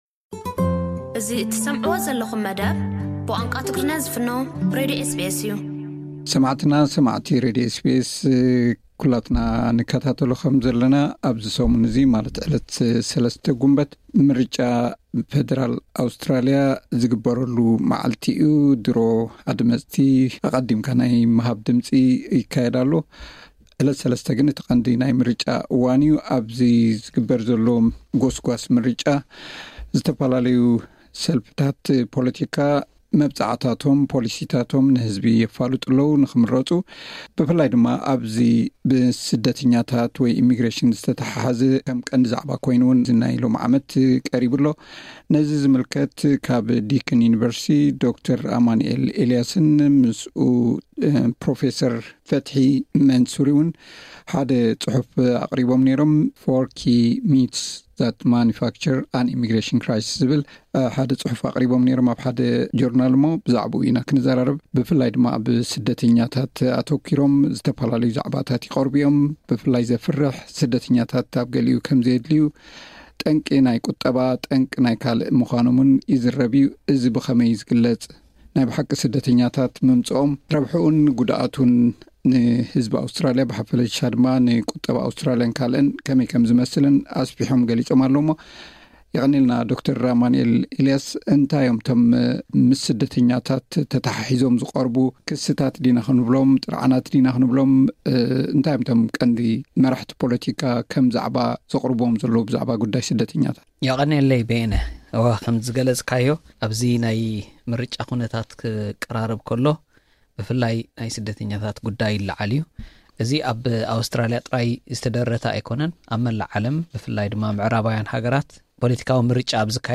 ዝርርብ ኣካይድና ኣለና።